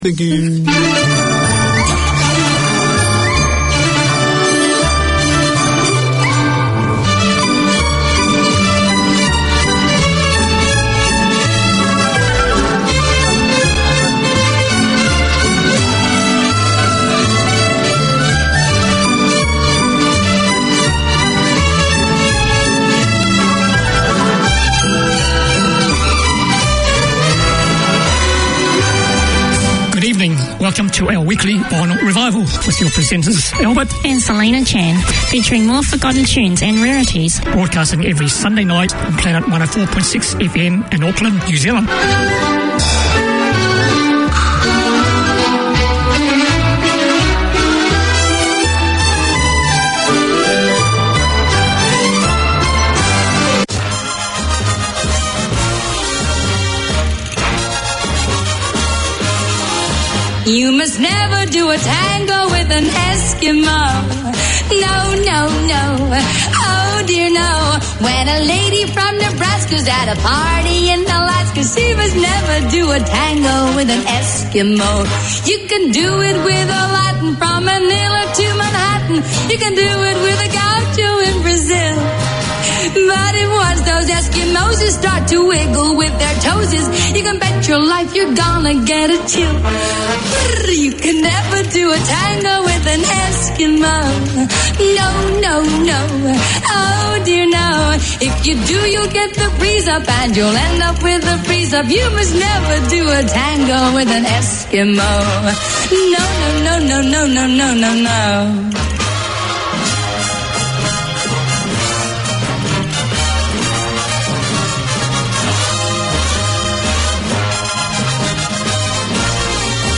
Listen for the best of the 30's,40's 50's,60's including hits and rarities.